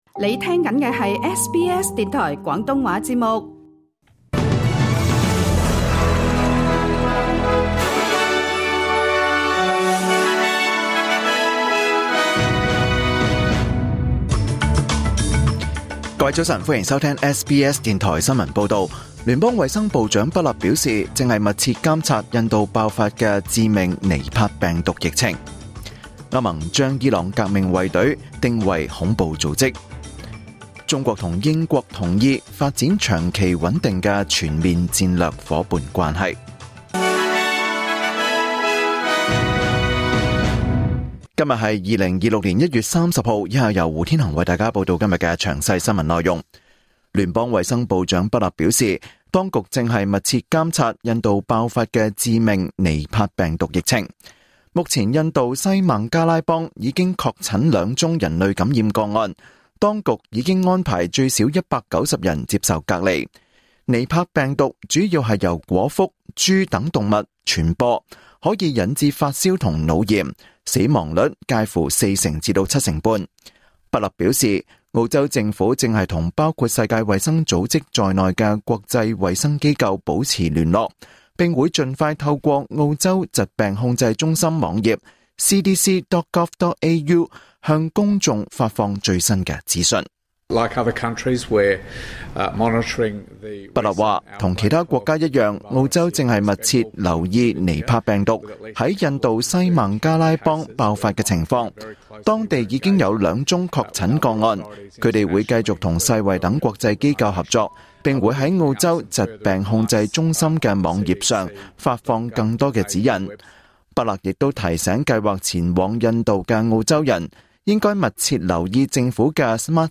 2026年 1 月 30 日SBS廣東話節目九點半新聞報道。